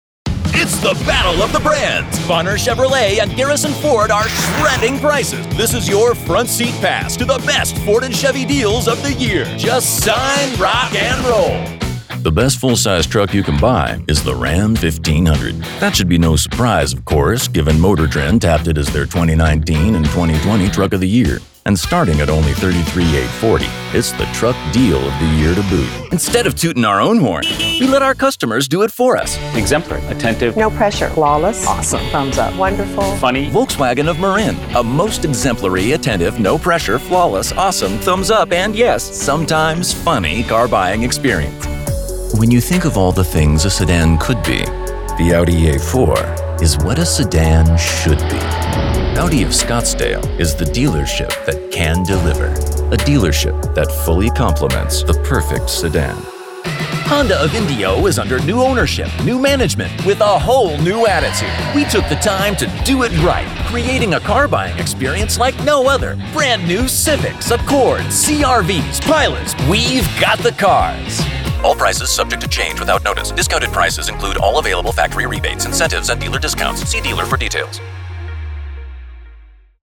Versatile North American male as at home with character acting as corporate narration. First class home studio.
Automotive Demo